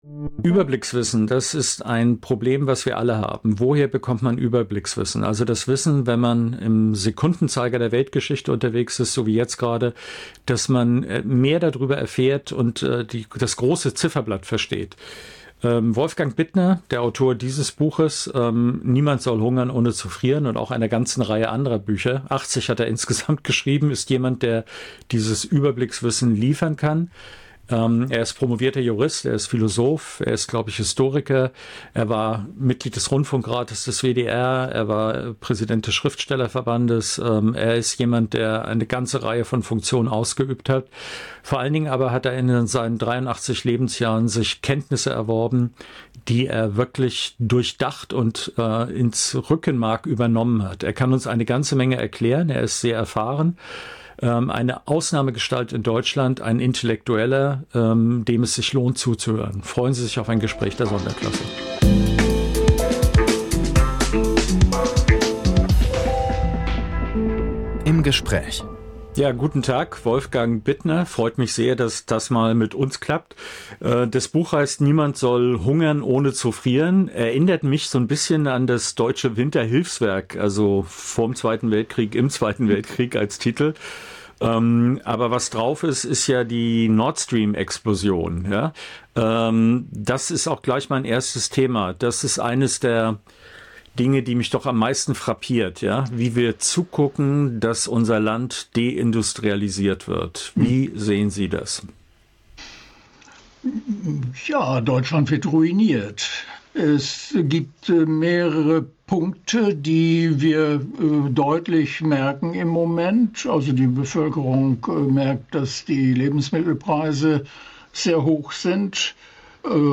Video-Interview